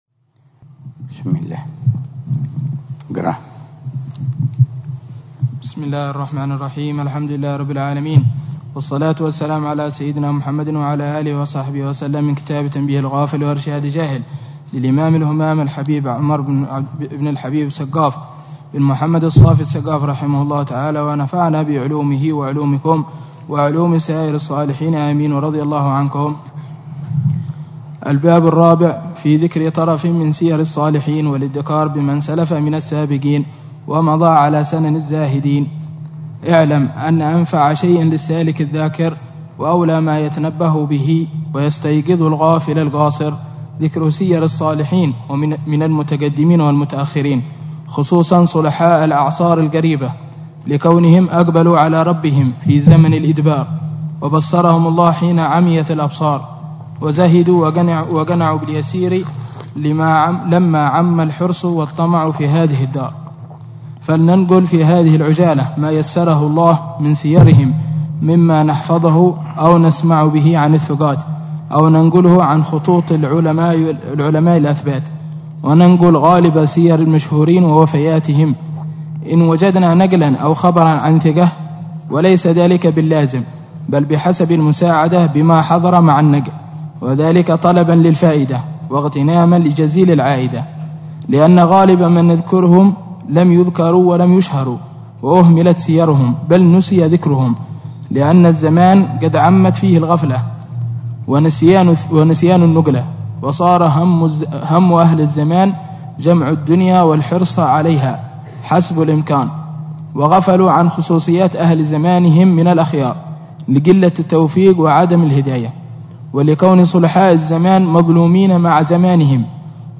الدرس السابع عشر من شرح العلامة الحبيب عمر بن محمد بن حفيظ لكتاب : تنبيه الغافل وإرشاد الجاهل للإمام الحبيب : عمر بن سقاف بن محمد الصافي السقا